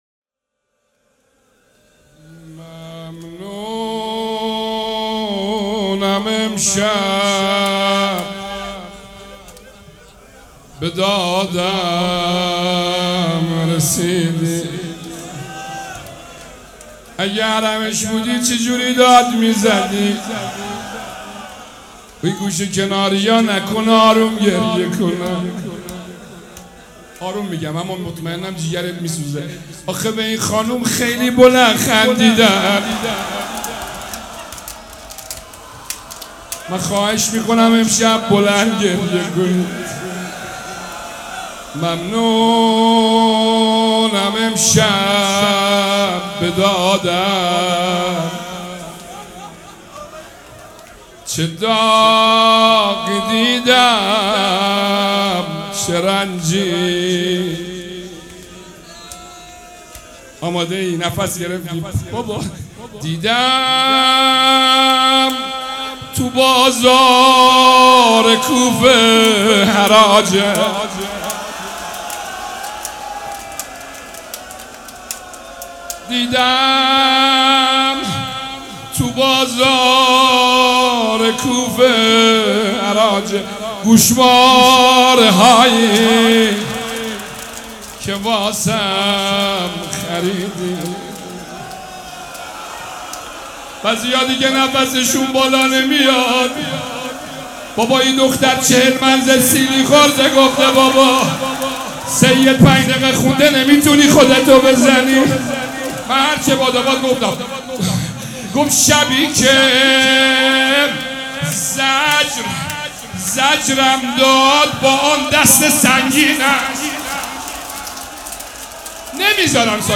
روضه شب سوم مراسم عزاداری صفر
سبک اثــر روضه